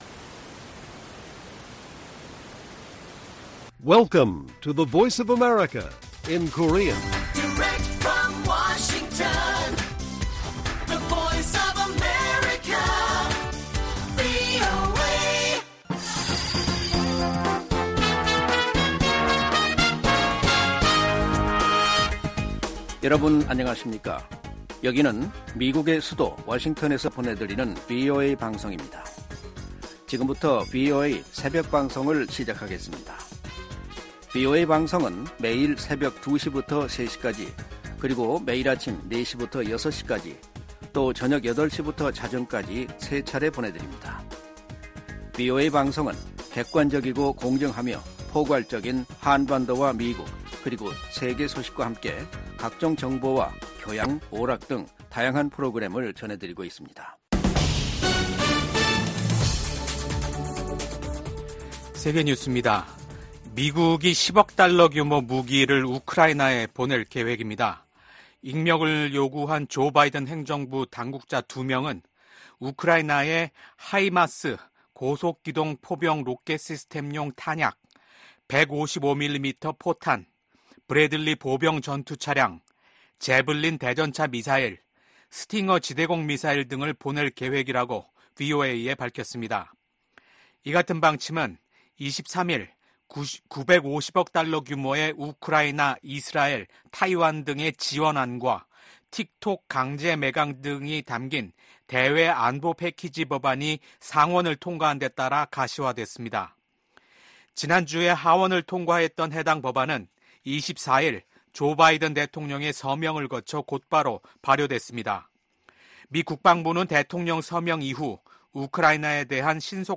VOA 한국어 '출발 뉴스 쇼', 2024년 4월 25일 방송입니다. 미 국무부는 북한의 핵반격훈련 주장에 무책임한 행동을 중단하고 진지한 외교에 나서라고 촉구했습니다. 미 국방부는 역내 안보를 위해 한국, 일본과 긴밀히 협의하고 있다고 밝혔습니다. 북한의 김여정 노동당 부부장은 올들어 이뤄진 미한 연합훈련 모두를 비난하며 핵 무력을 계속 비축하겠다고 밝혔습니다.